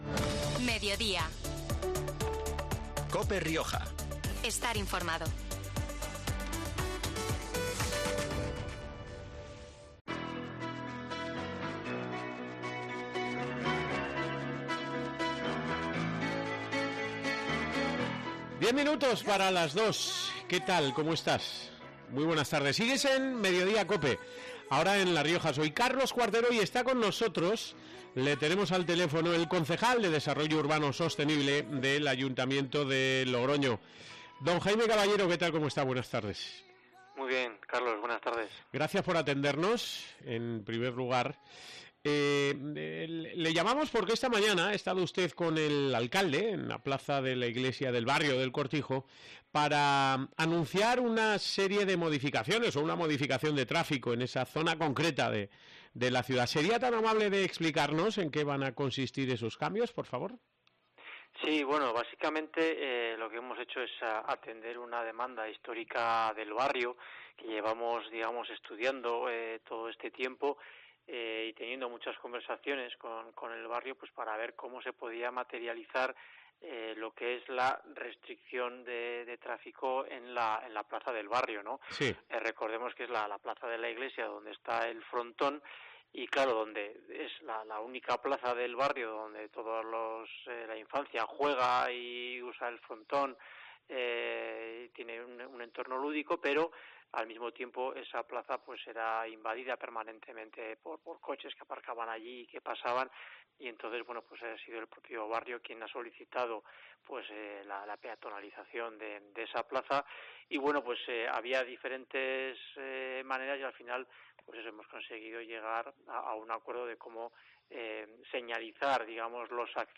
Jaime Caballero, concejal de Desarrollo Urbano Sostenible de Logroño, detalla en COPE esta actuación "históricamente demandada por la asociación de vecinos del barrio"